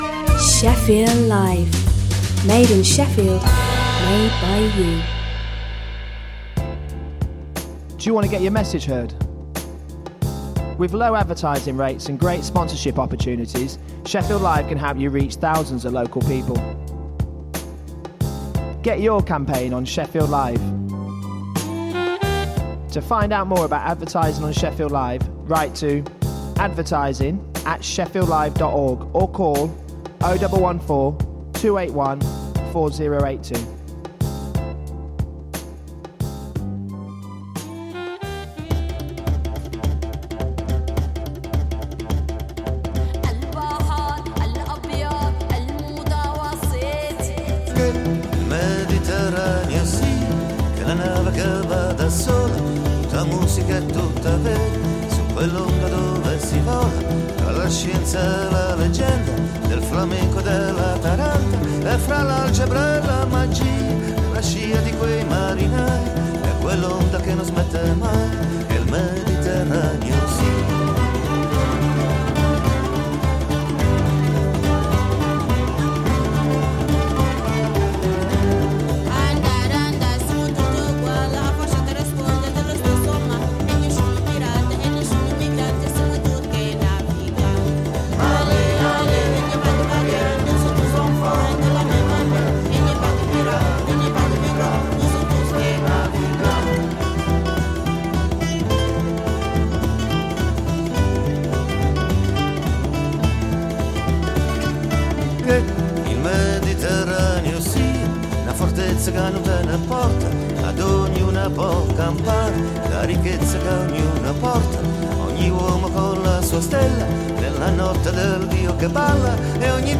Adal Voice of Eritreans is a weekly radio magazine programme for the global Eritrean community.